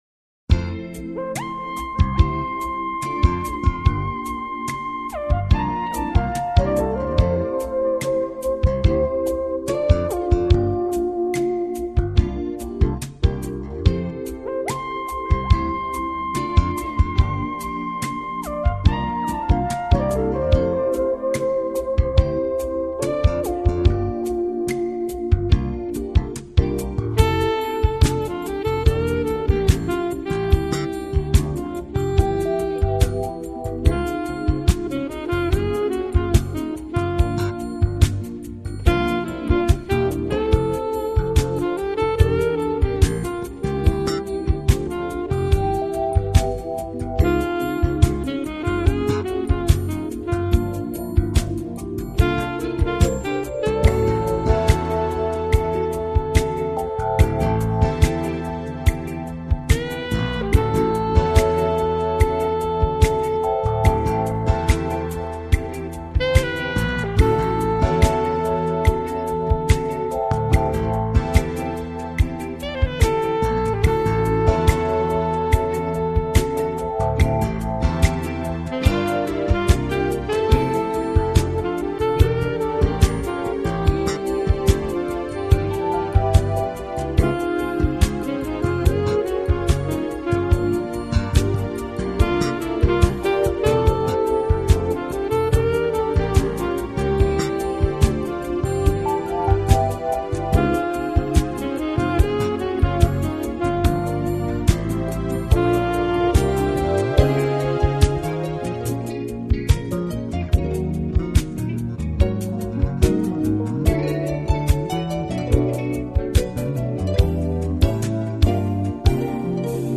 音乐类型：JAZZ
艺术个性和浪漫优雅的音乐风格显而易闻……